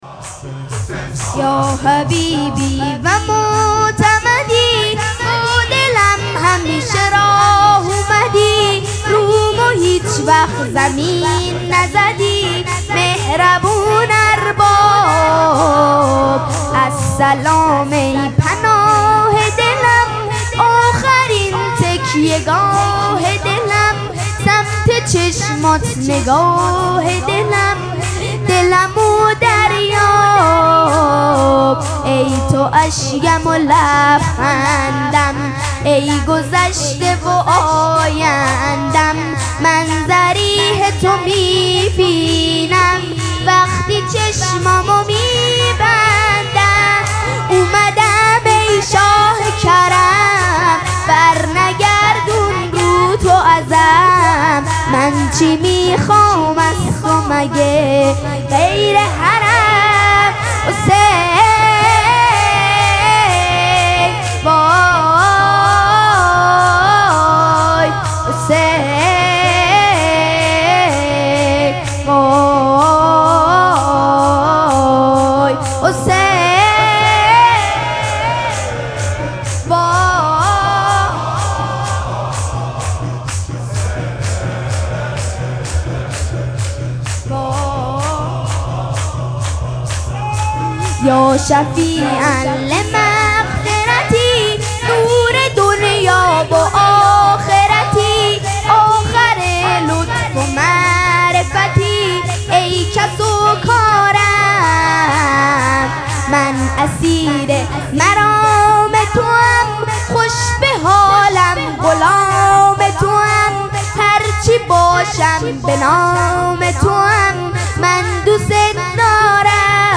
مراسم مناجات شب دوازدهم ماه مبارک رمضان
حسینیه ریحانه الحسین سلام الله علیها
شور